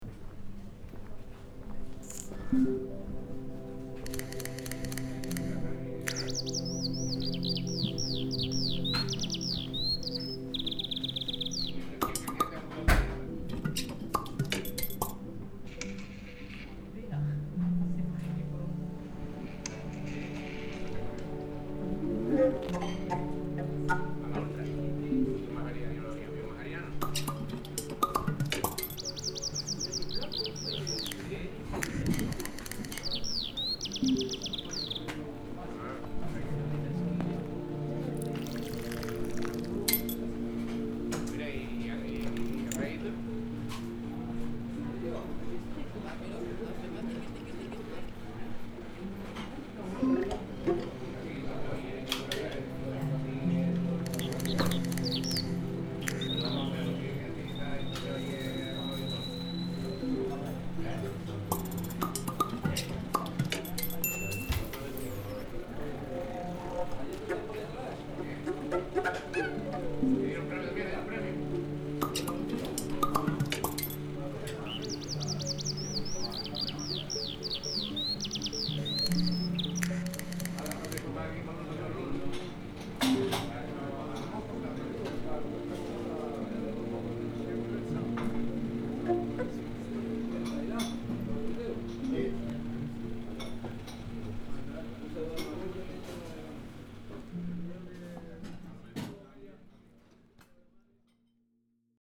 Det tredje ljudet är ett exempel på hur en abstrakt ljudmiljö kan låta för att främja kreativt arbete. Ljudexemplet är specialdesignat för oss och bygger på aktuell forskning.